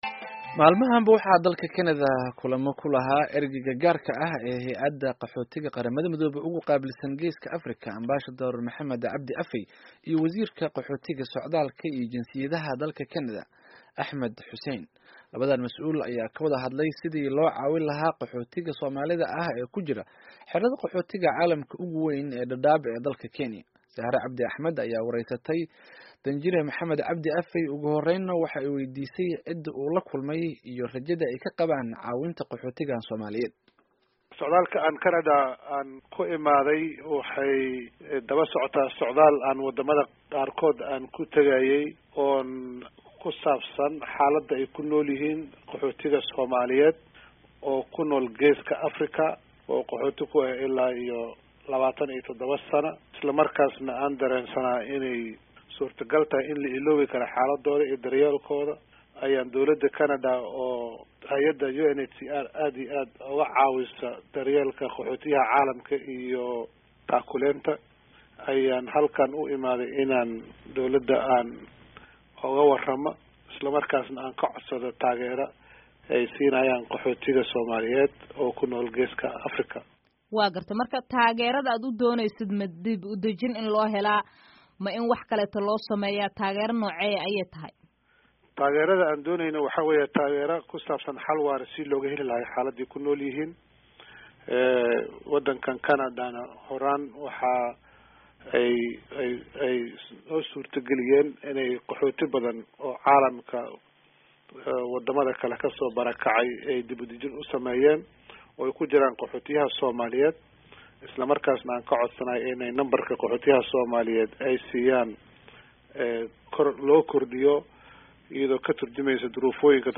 Wareysi: Danjire Maxamed C. Afey